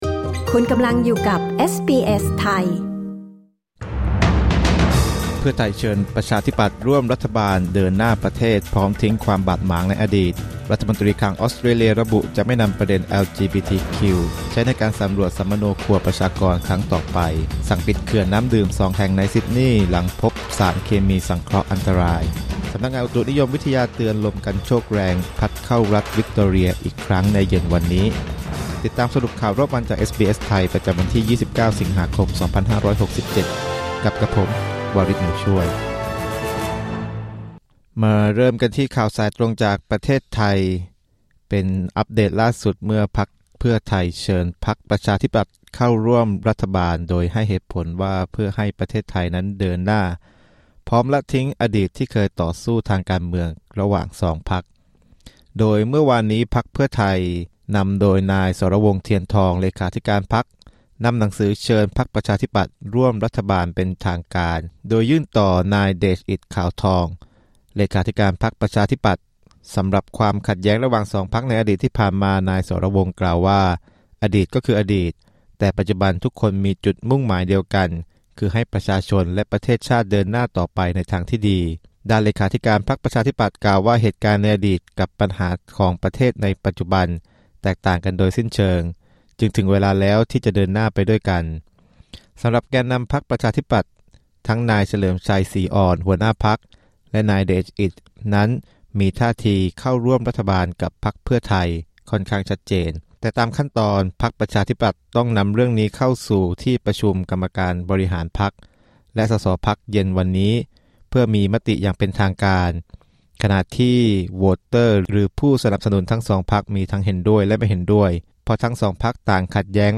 สรุปข่าวรอบวัน 29 สิงหาคม 2567